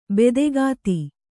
♪ bede gāti